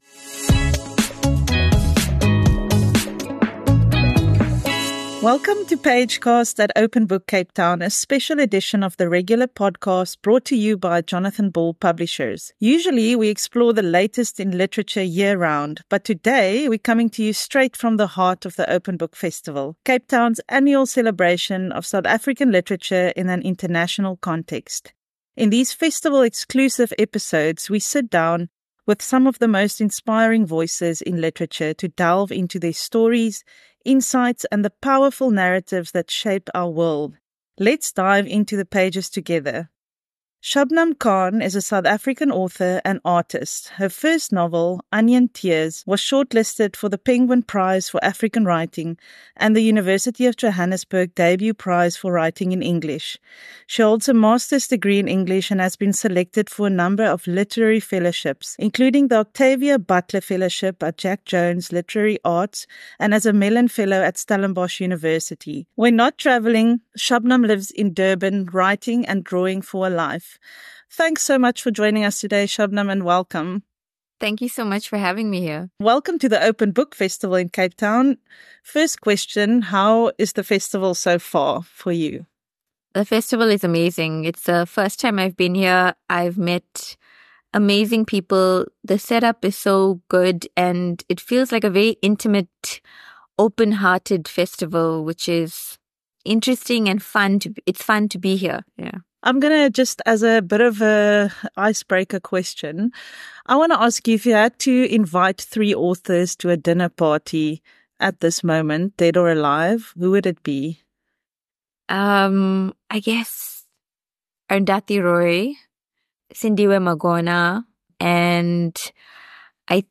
Welcome to Pagecast at Open Book Cape Town, a special edition of the regular podcast brought to you by Jonathan Ball Publishers. Usually, we explore the latest in literature year-round, but in this episode, we're coming to you straight from the heart of the Open Book Festival—Cape Town's annual celebration of South African literature in an international context. In these festival-exclusive episodes, we sit down with some of the most inspiring voices in literature to delve into their stories, insights, and the powerful narratives that shape our world.